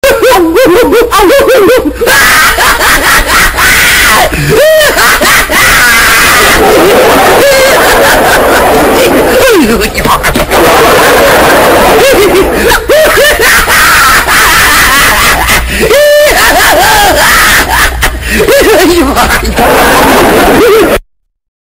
Risada do kiko ESTOURADA
Categoria: Risadas
É o som de uma gargalhada registrada em alto volume, que deixa o áudio distorcido e quase inaudível.
risada-do-kiko-estourada-pt-www_tiengdong_com.mp3